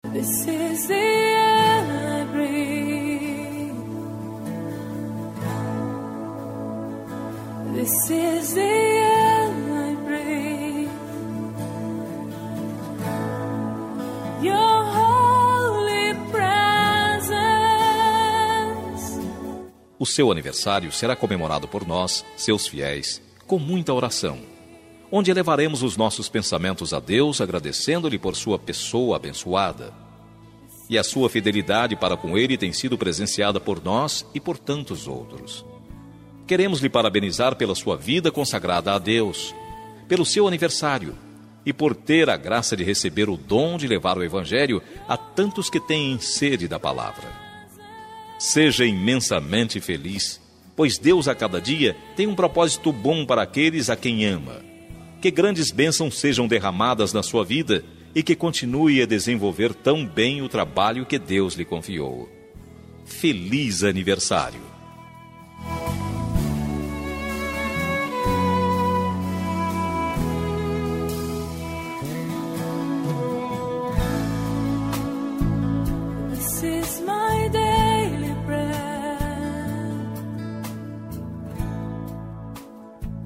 Aniversário de Pastora – Voz Masculina – Cód: 5111